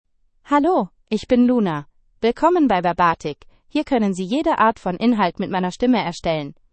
LunaFemale German AI voice
Luna is a female AI voice for German (Germany).
Voice sample
Listen to Luna's female German voice.
Female
Luna delivers clear pronunciation with authentic Germany German intonation, making your content sound professionally produced.